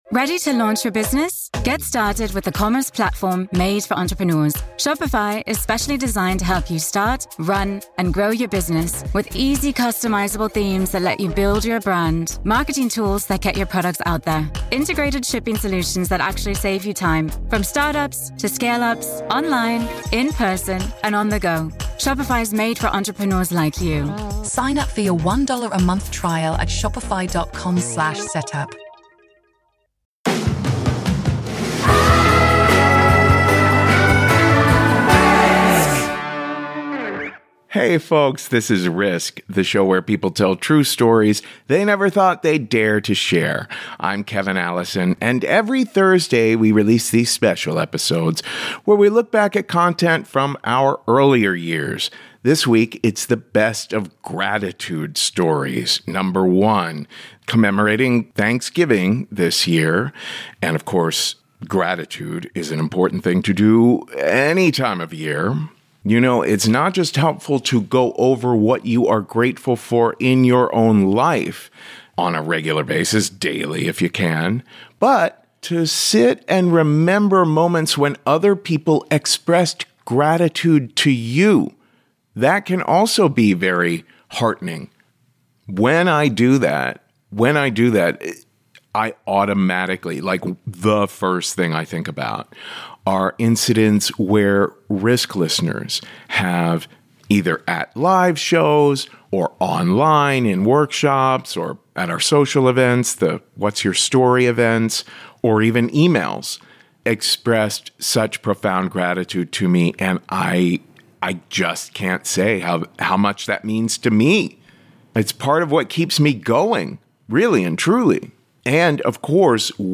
🎤Live Story: My Lifeline